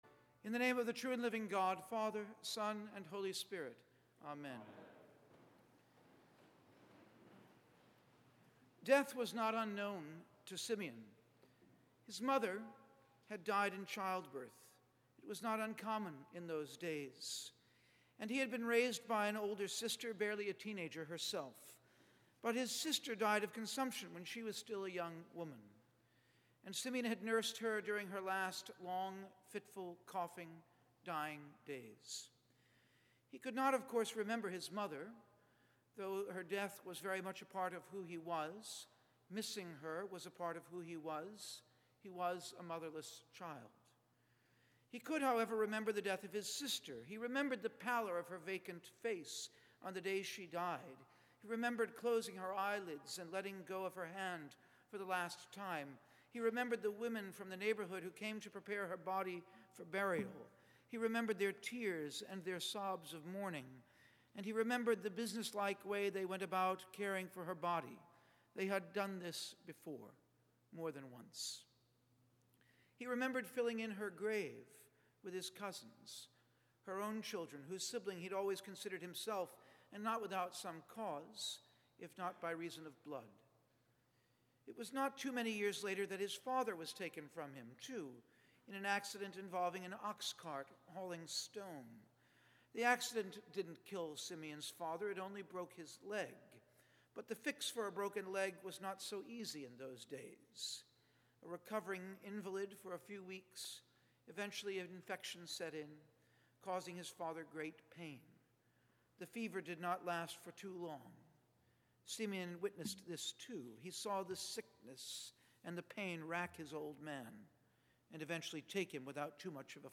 Feb 2 SEM Sermon.mp3